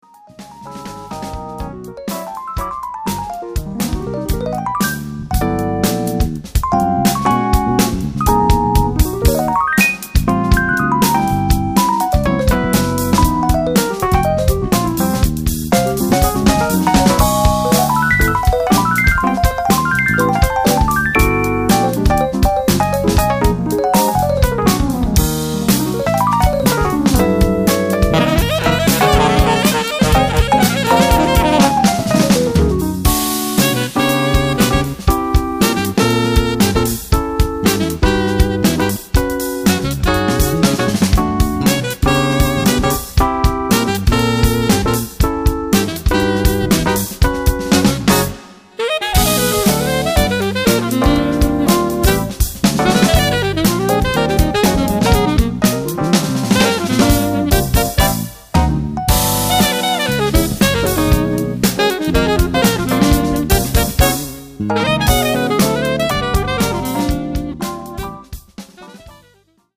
sax alto
piano, piano elettrico
contrabbasso, basso elettrico
batteria